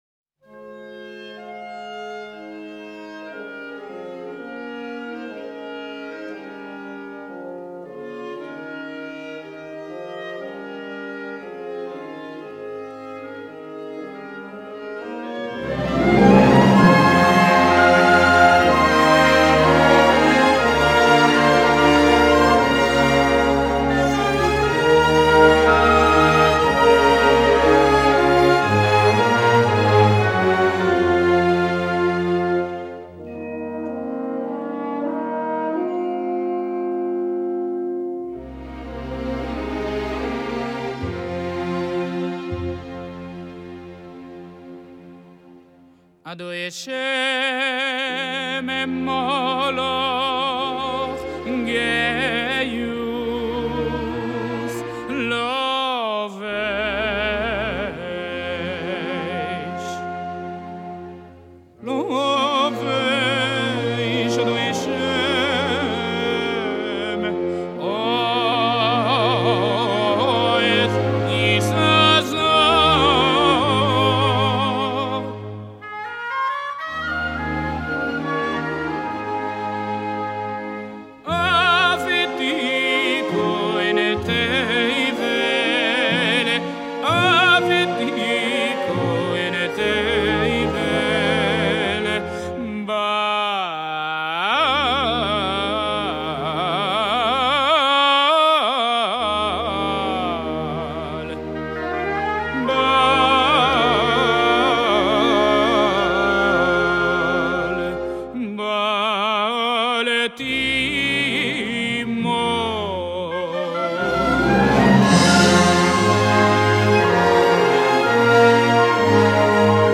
הן בקולו הגבוה והנעים והן באמירתו העמוקה והמכוונת.